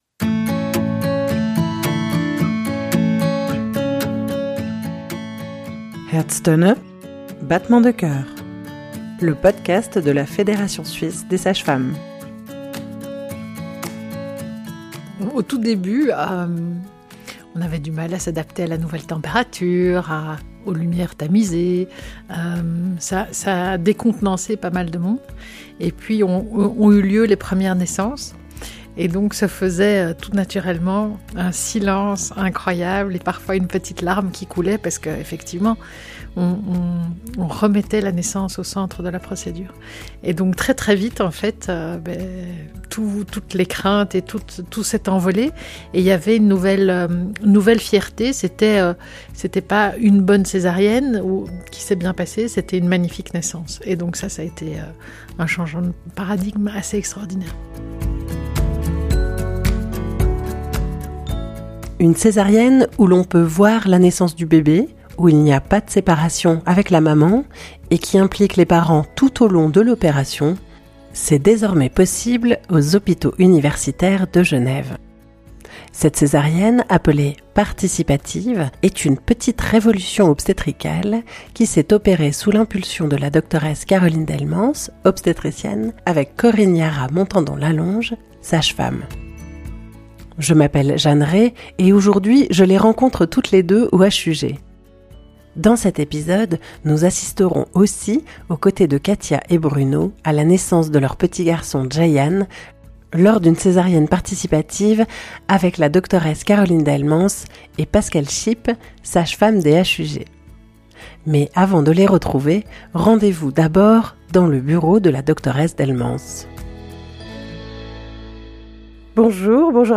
Reportage: la césarienne participative, aux Hôpitaux universitaires de Genève ~ Herztöne – der Hebammen-Podcast Podcast